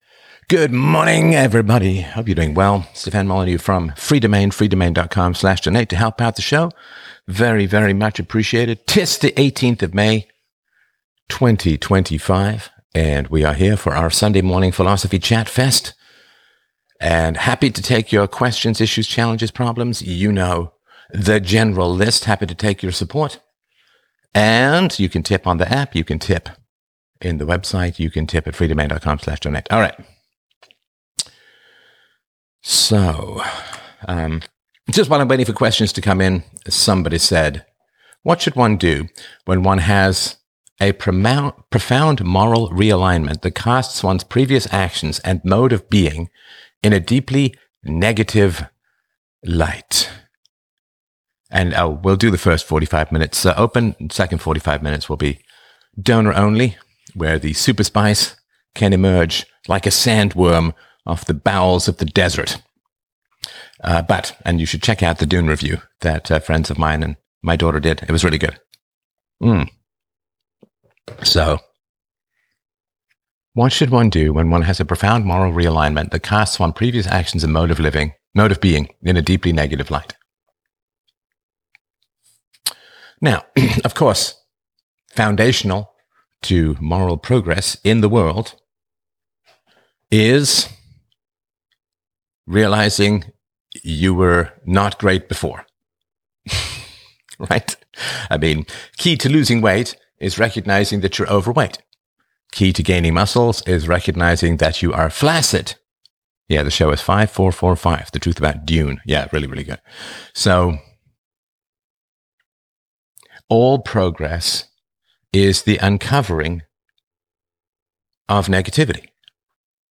… continue reading 1 5958 What to Do When We Do Wrong! 45:26 Play Pause 1h ago 45:26 Play Pause Play later Play later Lists Like Liked 45:26 Sunday Morning Live 18 May 2025 In this episode, I delve into the journey of moral realignment and self-reflection, discussing the challenges of re-evaluating past actions following a moral awakening.